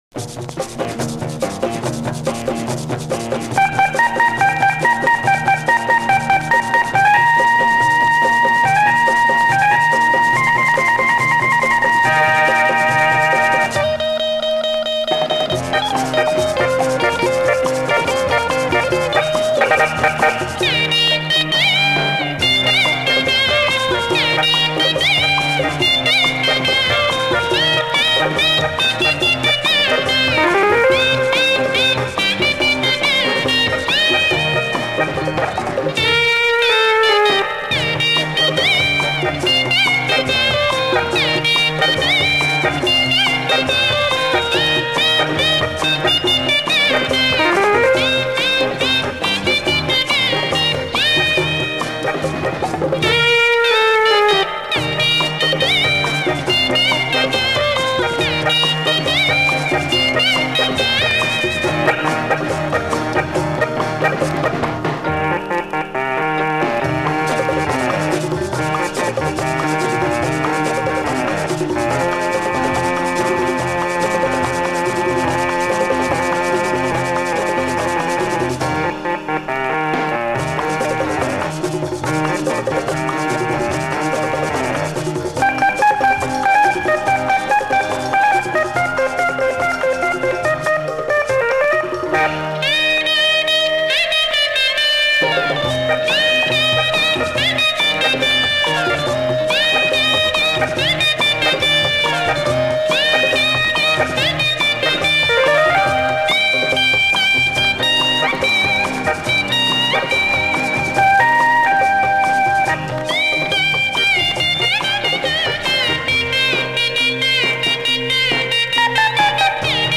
all Hindi film tunes.